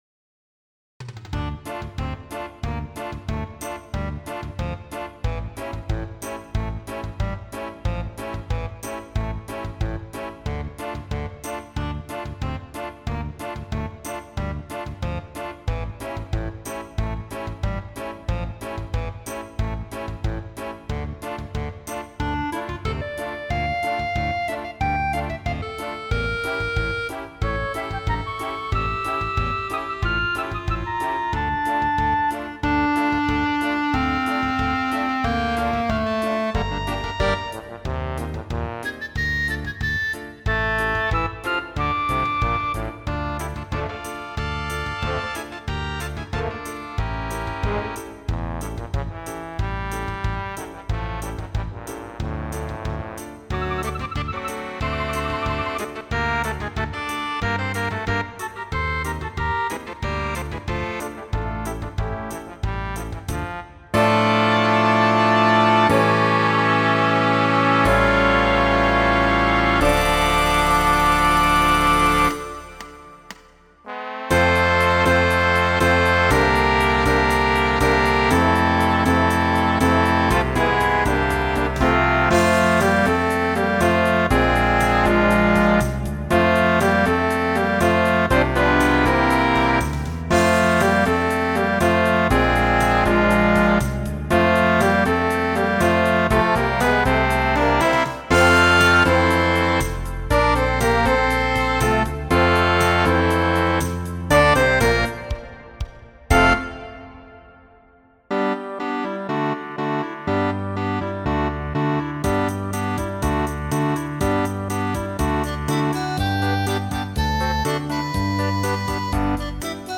Harmonieorkest, Piano